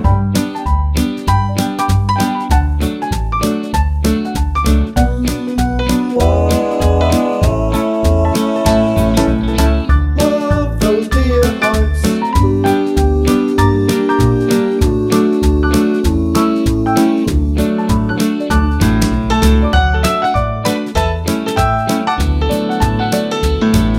no Backing Vocals Easy Listening 2:03 Buy £1.50